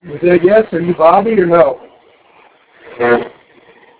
We did conduct several E.V.P sessions in several rooms but our spirit box session  in one small bedroom, revealed several responses to our questions.
There were more audio clips but these were clearest sounding of the bunch.